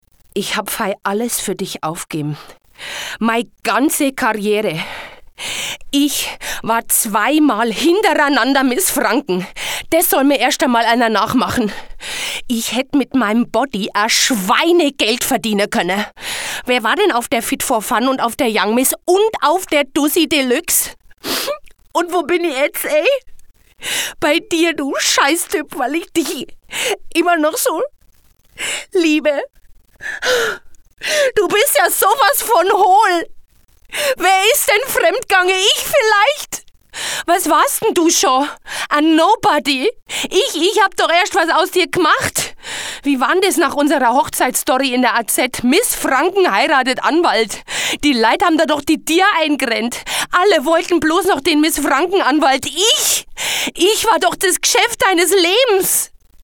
Reisebericht